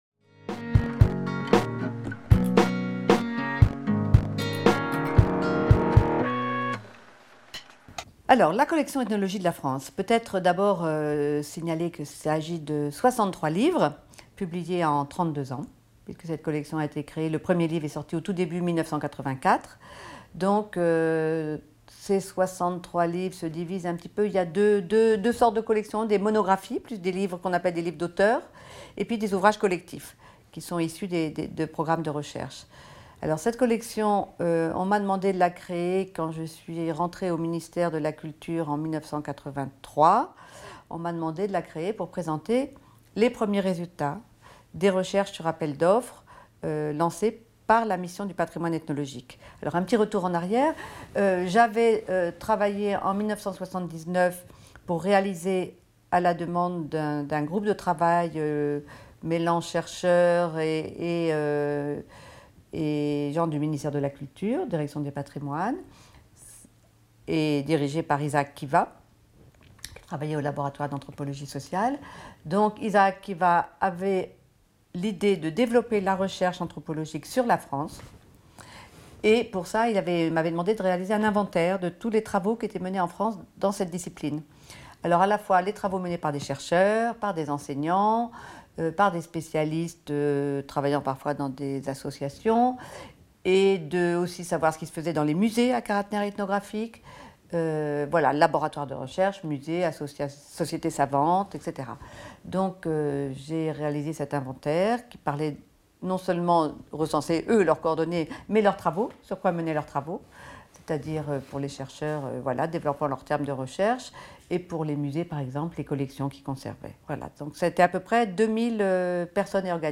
Journée "Ethnologie de la France", interview